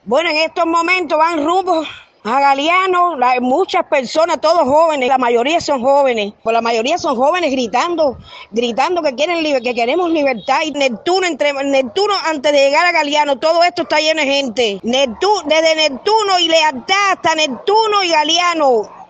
Desde Centro Habana